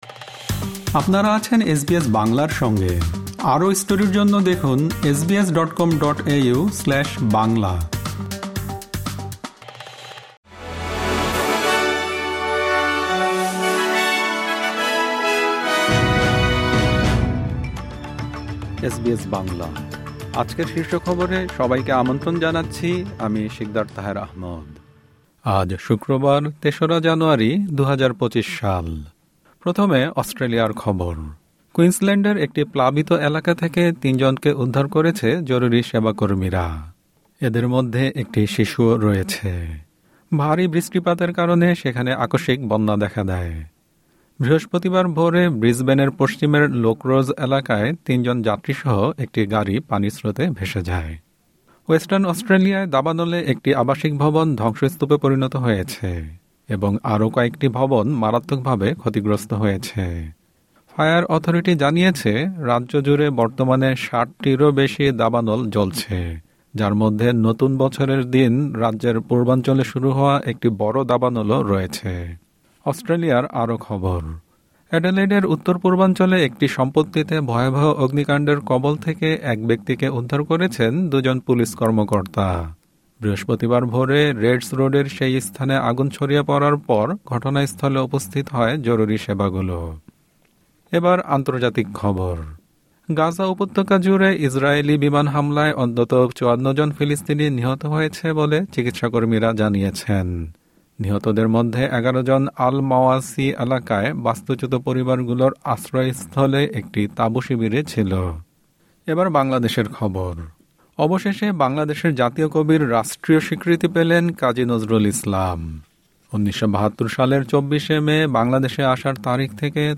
এসবিএস বাংলা শীর্ষ খবর: ৩ জানুয়ারি, ২০২৫।